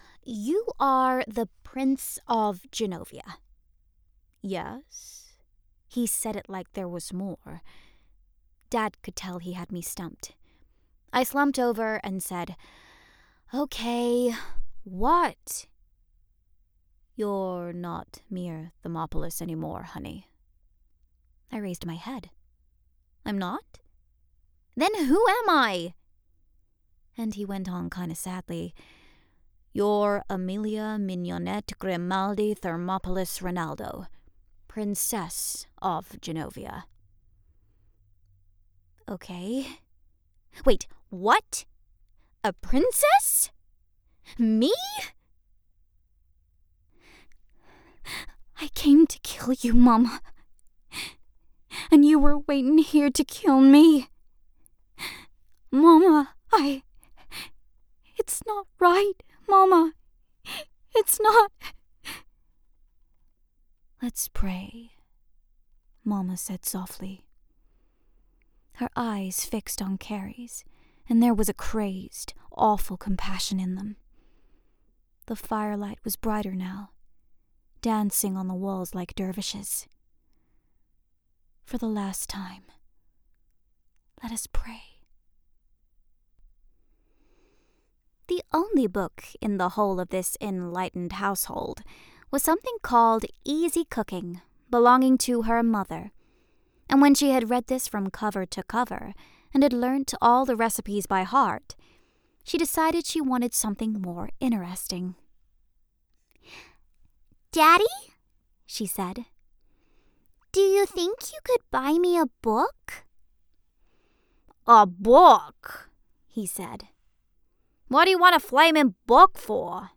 US Accent Reel
A naturally youthful quality gives her access to a variety of teen voices, making her an easy choice for animation and video games.